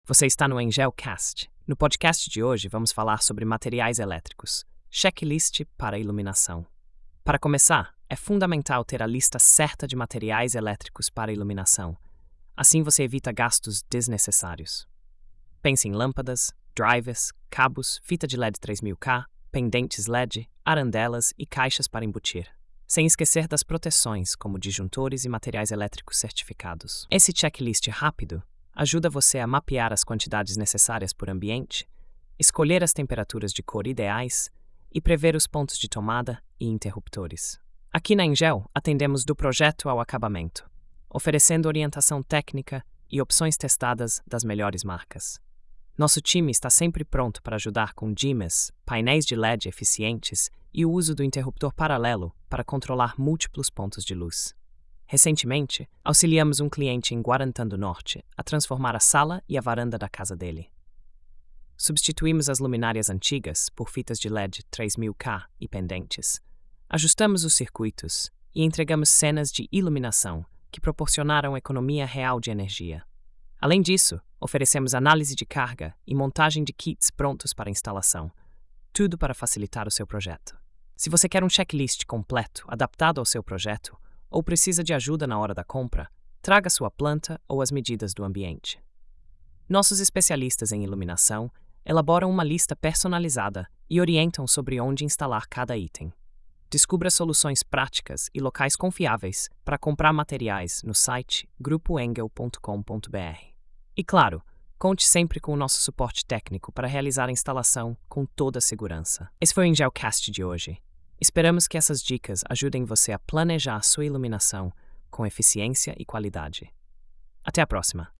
Narração automática por IA • Construção & Reformas